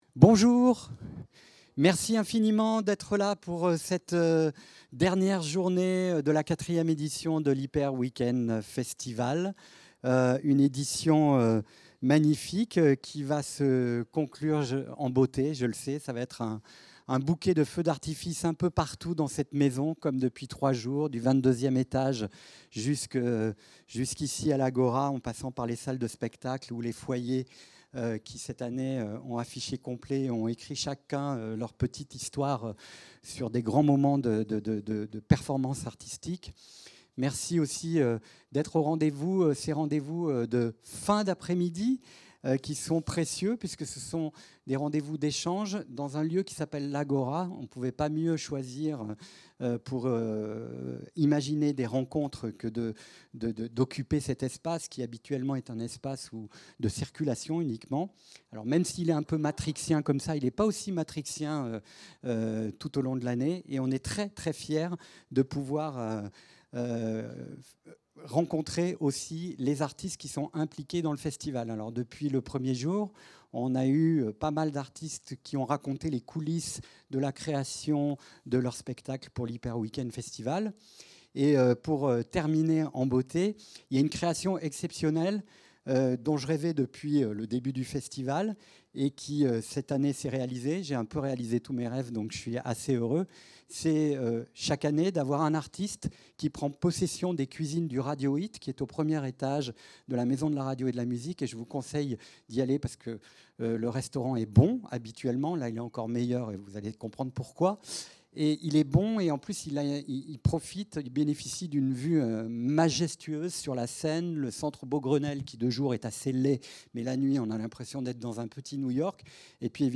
Conversation avec Laurent Garnier | Hyper Weekend Festival de Radio France
Conversation avec Laurent Garnier | Hyper Weekend Festival de Radio France.